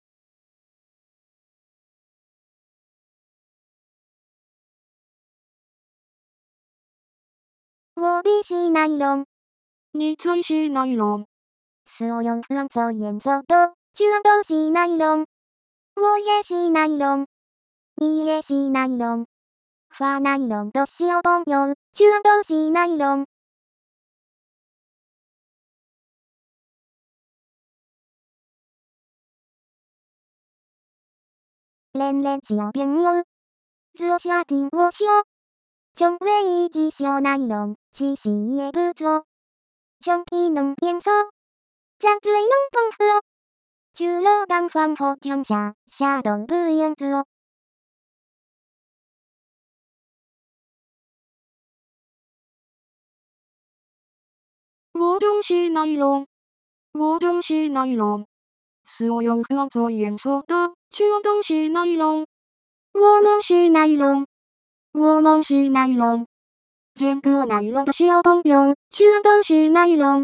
这个还是半成品，还有一段没调，而且因为不会编曲所以也没有伴奏
前面的空白是留给伴奏的，正片跳转00:07